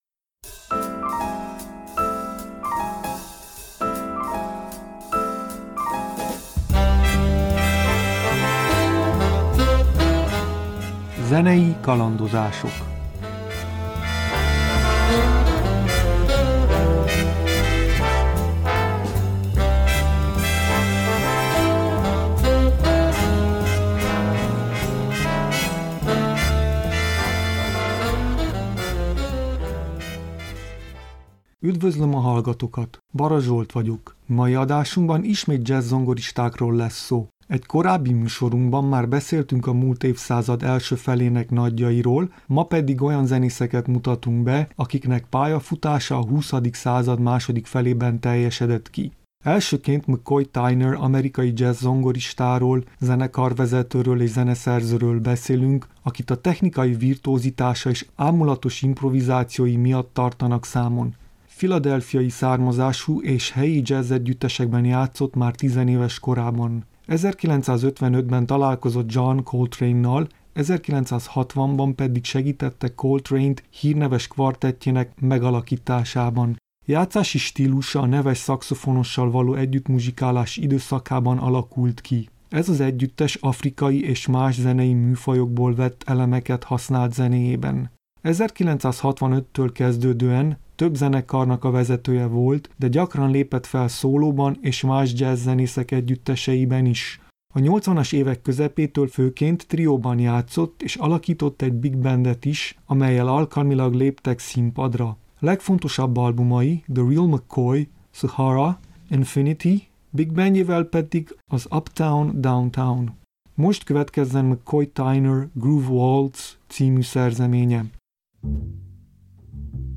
09.Jazz-zongora-2.mp3